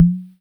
Urban Tom 01.wav